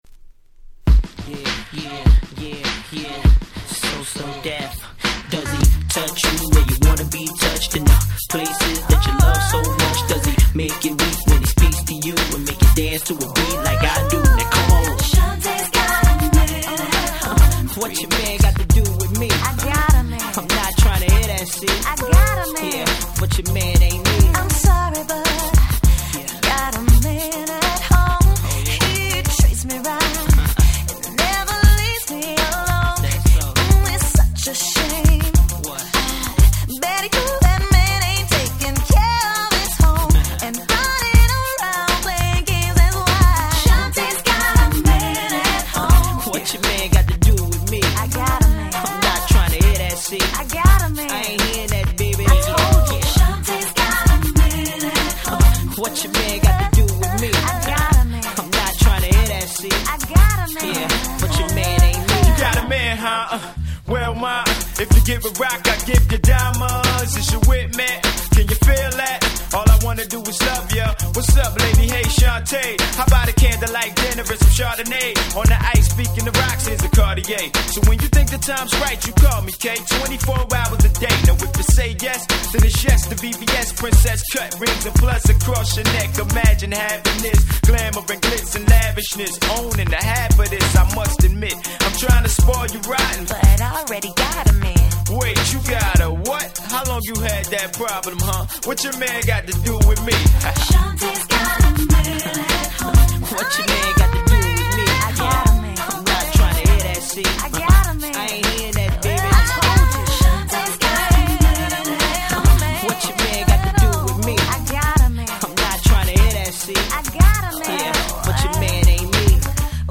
99' Nice R&B !!
この曲、元は確かSlowだった気がしますが・・・。